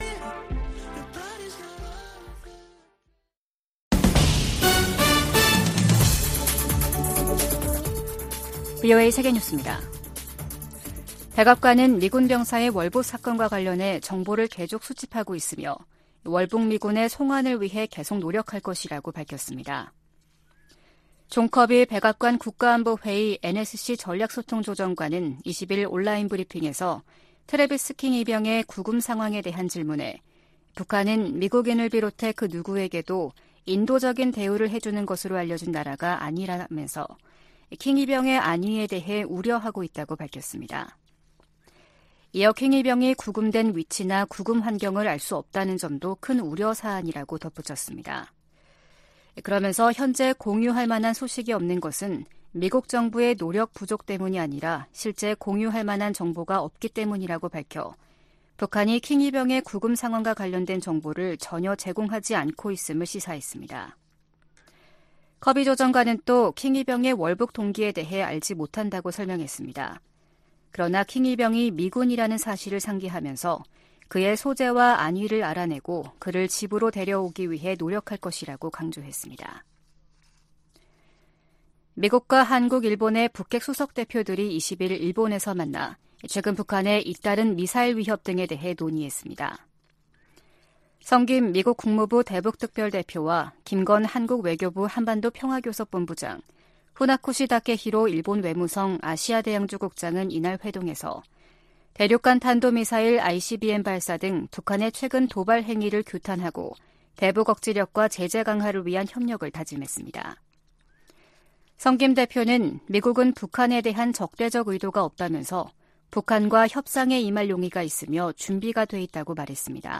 VOA 한국어 아침 뉴스 프로그램 '워싱턴 뉴스 광장' 2023년 7월 21일 방송입니다. 미국과 한국, 일본 정상회의가 다음달 캠프데이비드에서 열립니다.